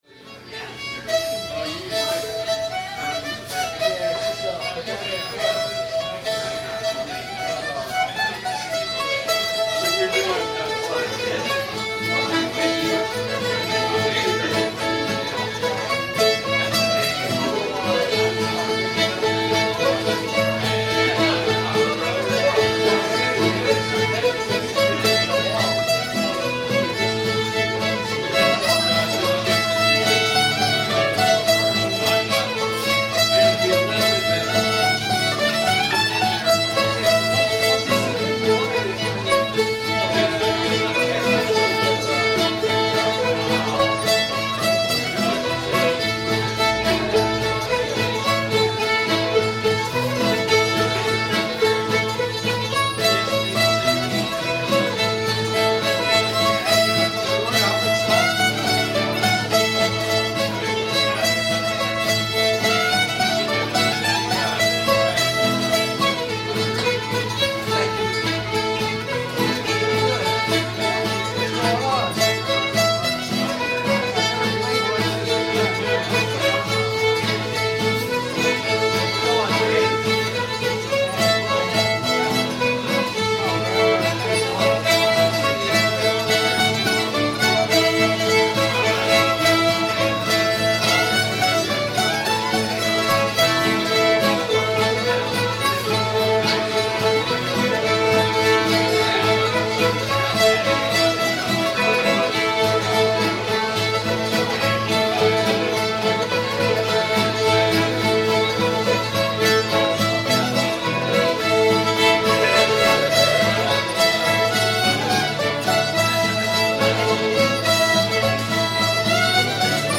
railroad runs through georgia [A]